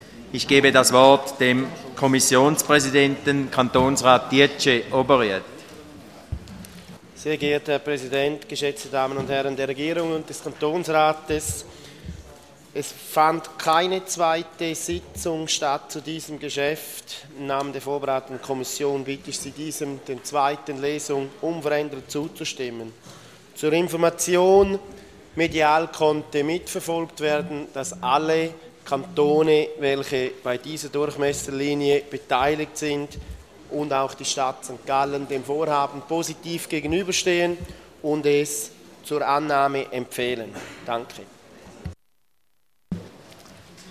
25.2.2013Wortmeldung
Session des Kantonsrates vom 25. bis 27. Februar 2013